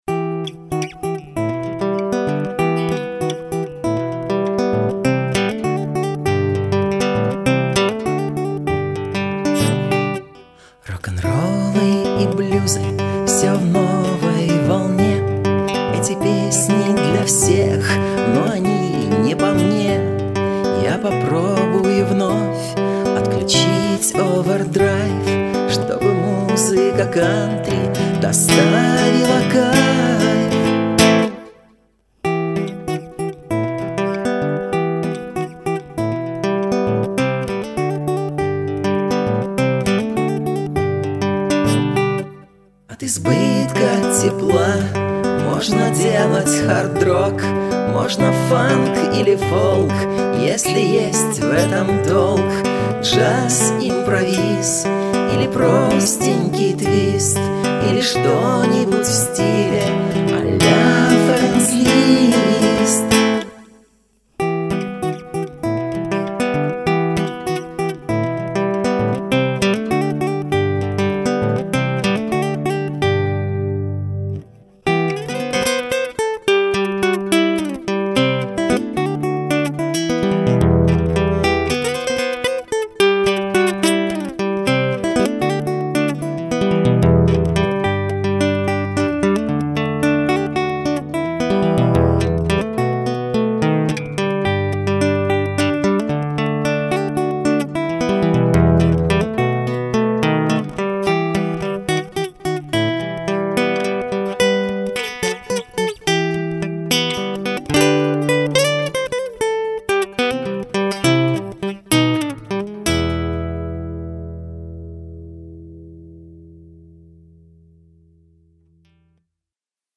Есть в альбоме и Рок`н`Ролл
вокал, бэк вокал, акустические гитары